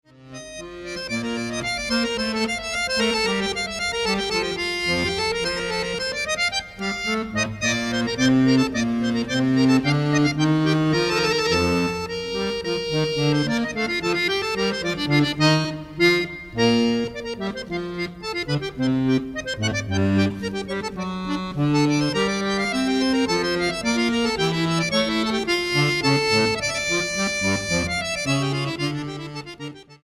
acordeón clásico